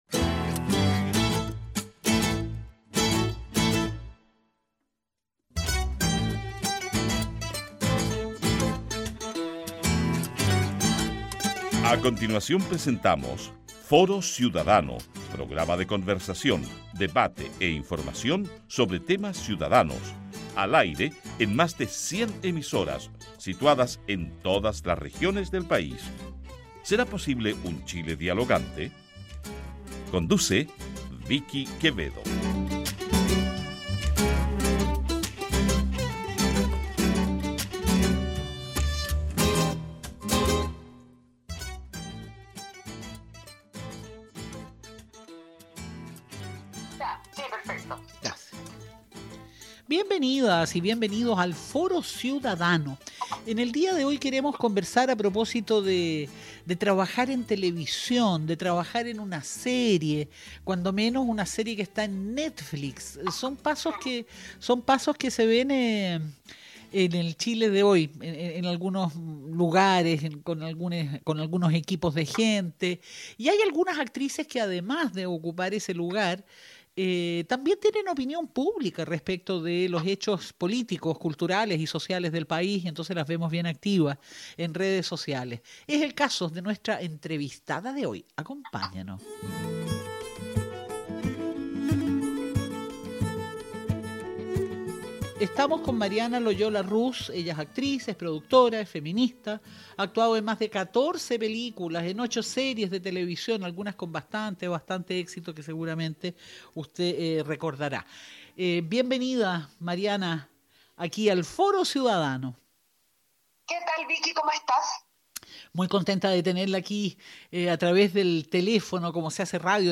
De Tv, cine, plataformas internacionales, entre otros, conversamos con Mariana Loyola Ruz, actriz, productora, feminista. Ha actuado en mas de 14 películas, en 8 series de TV algunas con bastante éxito.